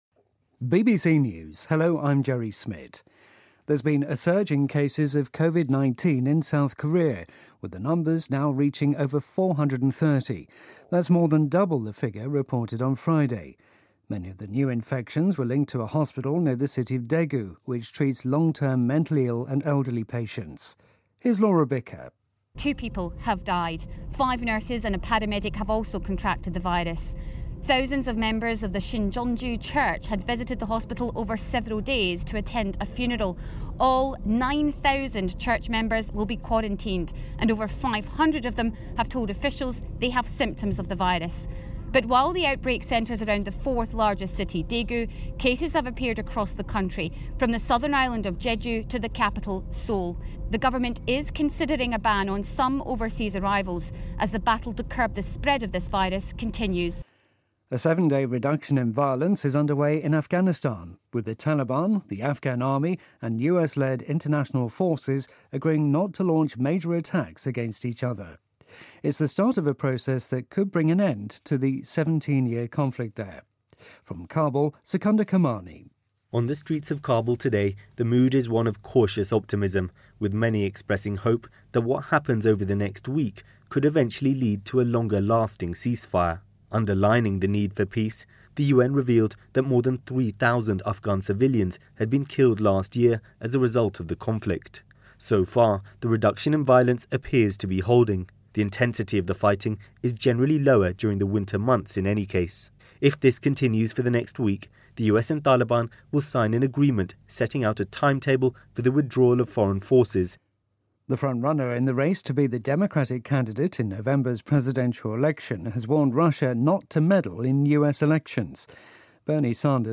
News
英音听力讲解:韩国的新型冠状病毒肺炎确诊病例激增